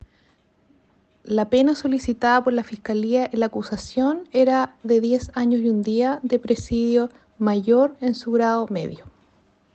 Fiscal Claudia Baeza